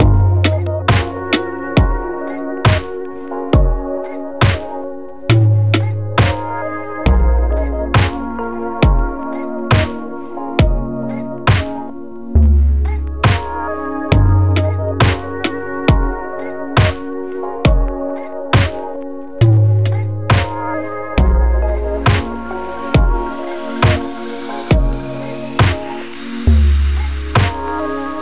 Popcorn Ping
Bumpy Thump
Bb Minor
Alt Crunch
Chord Seeker